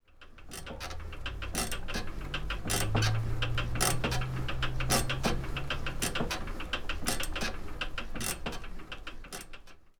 Stepper
Nein, auch wenn der/die gewillte HörerIn ein knarrendes Bett vermutet, ist es ein in die Jahre gekommener Stepper, der seine Karriere im Fitnesscenter bereits abgeschlossen hat und seine letzten Tage im längst verlassenen Kinderzimmer gemeinsam mit einem einsamen Hausmann in Pantoffeln und Pyjama im Kampf gegen die Kilos verbringt.